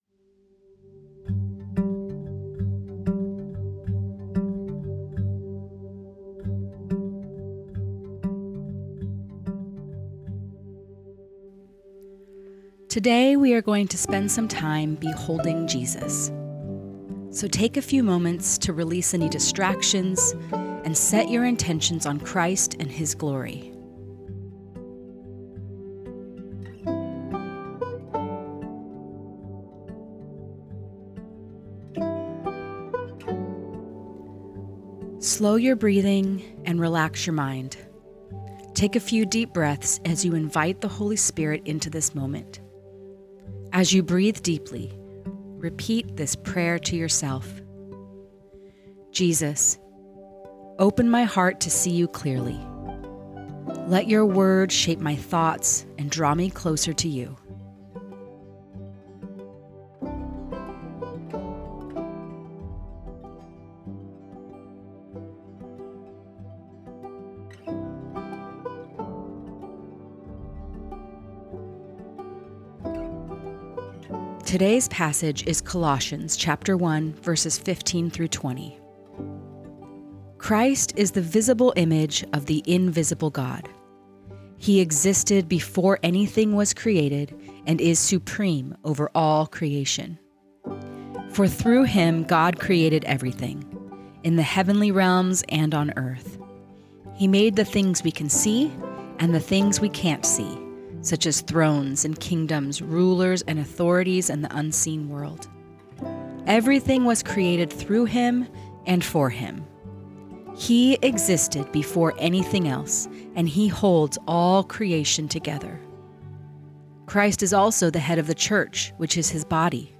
Guided Listening Practice